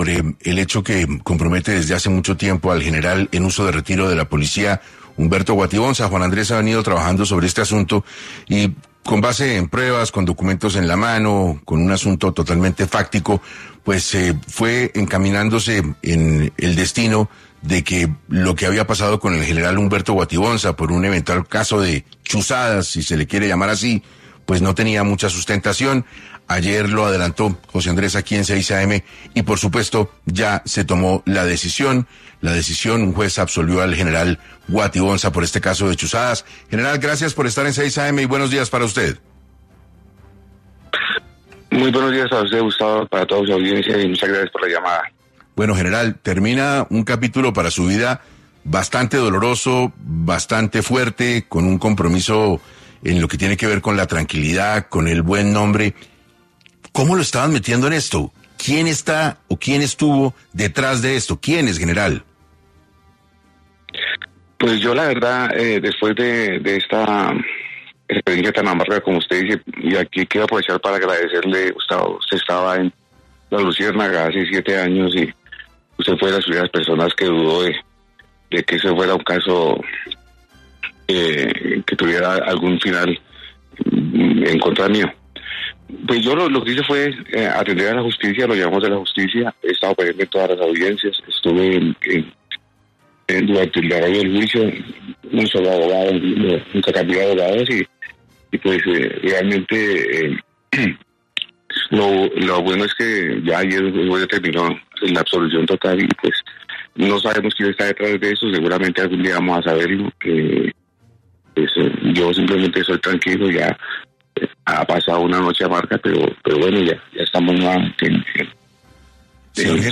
En 6AM de Caracol Radio habló General (r) Humberto Guatibonza y excomandante de la policía Metropolitana de Bogotá, quien enfatizó que no había pruebas sobre el supuesto software que interceptaba celulares en el que lo involucraron